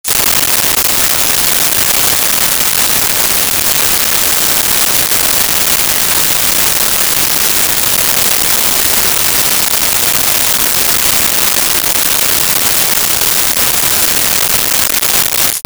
Bats In A Cave
Bats in a Cave.wav